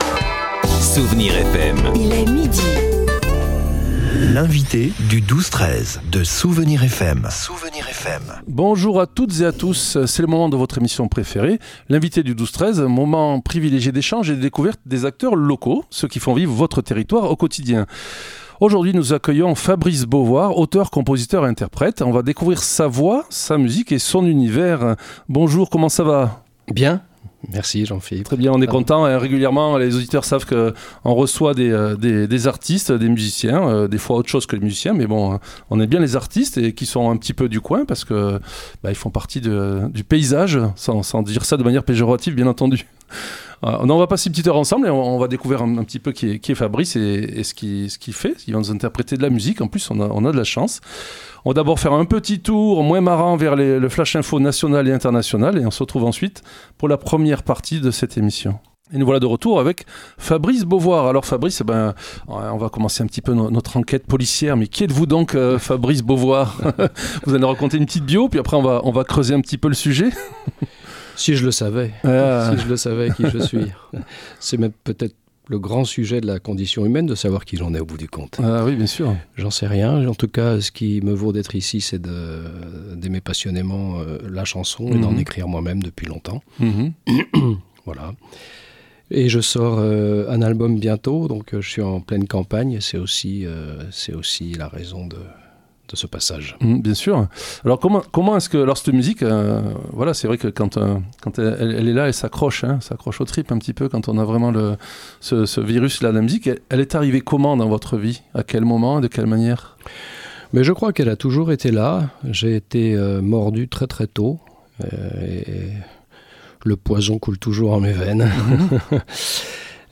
Plein de chansons et de textes.